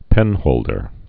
(pĕnhōldər)